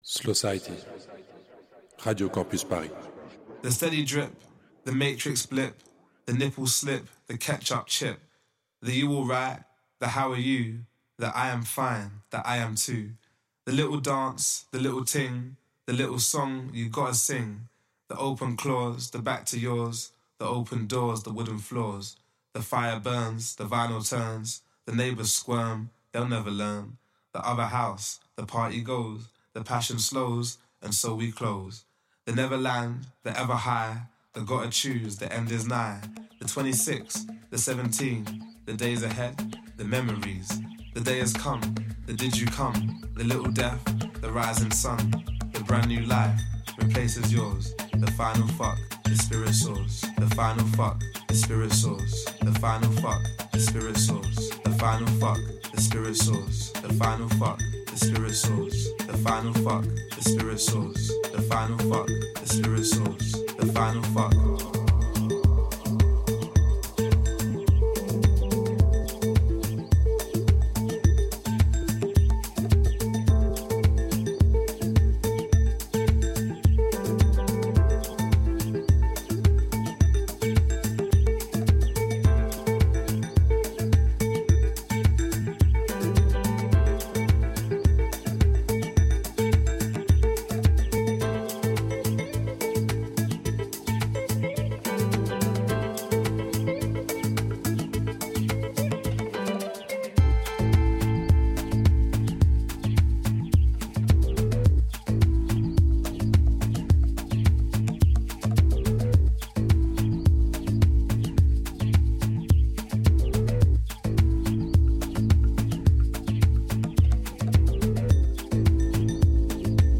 dj-set
vitesse de croisière : 107 BPM.